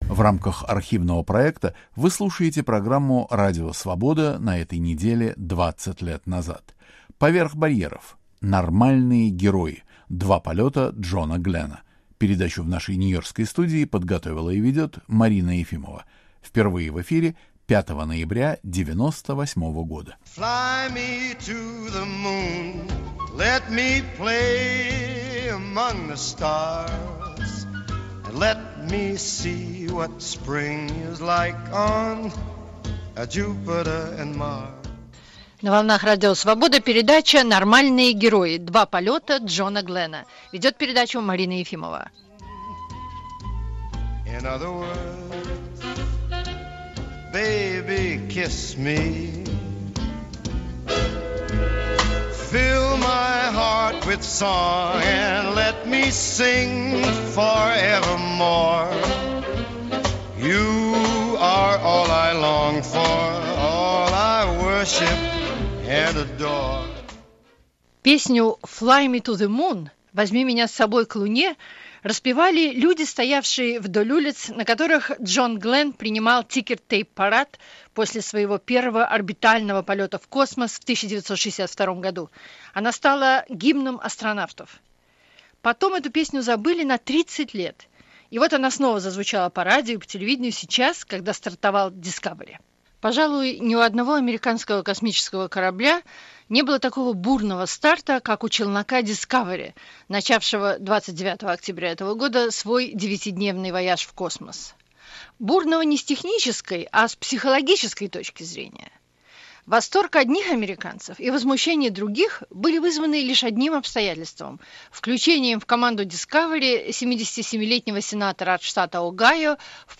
Пит Конрад и Фрэнк Борман - астронавты